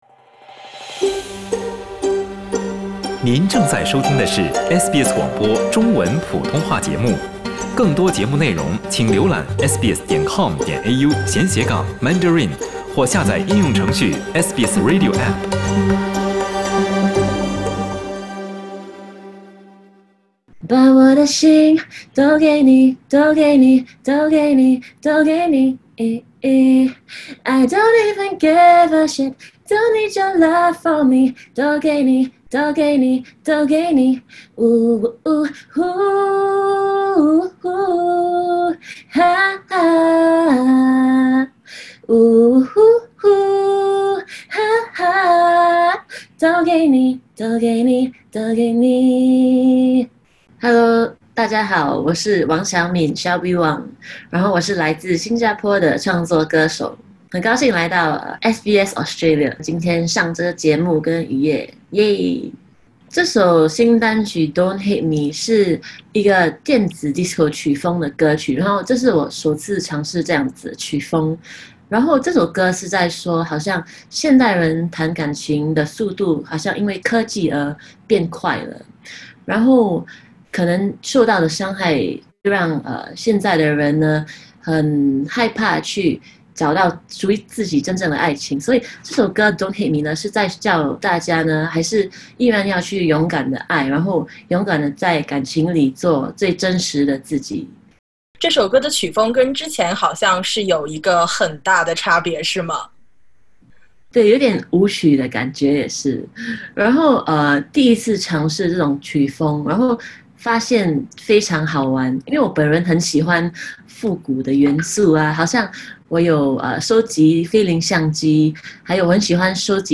点击封面音频，收听完整采访及歌曲。